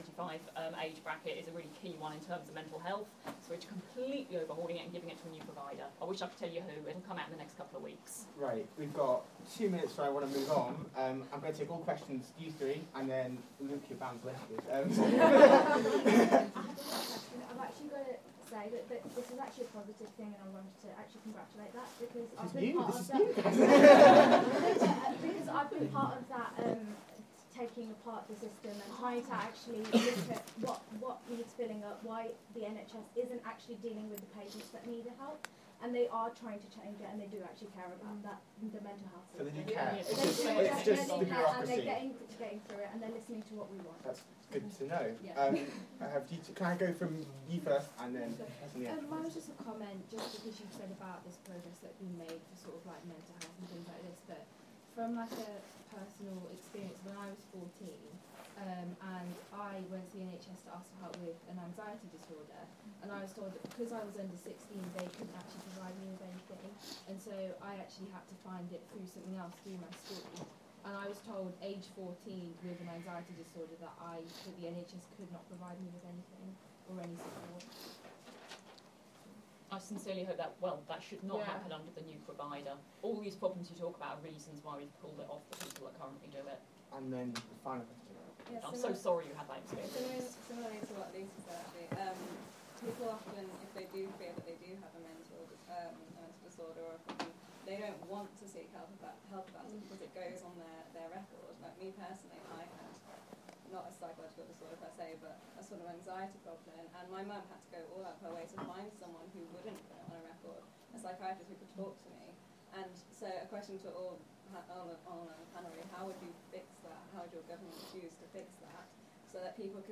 UpRising hustings (1)